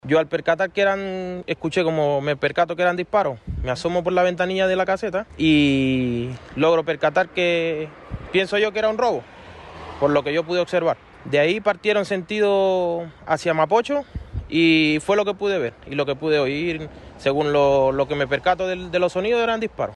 Un trabajador que presenció el robo, comentó que se escucharon múltiples disparos.
cu-trasnoche-1-testigo.mp3